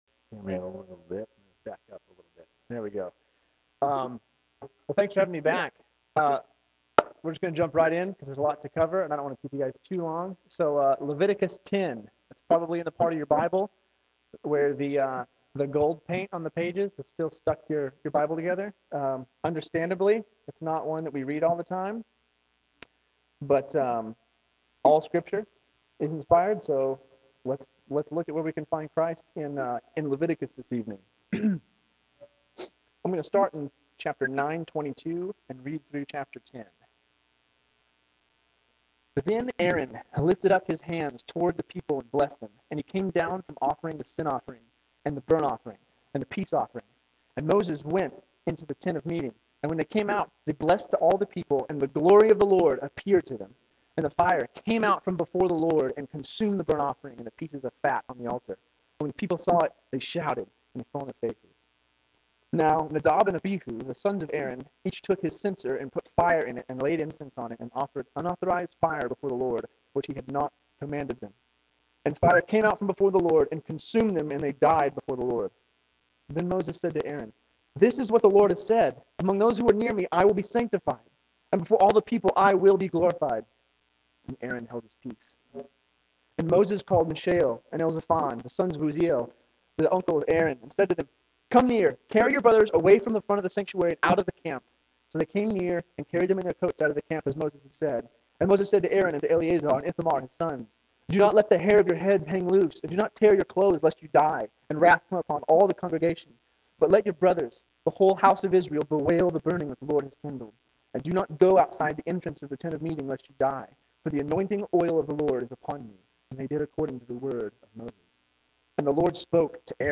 November 8, 2009 PM Service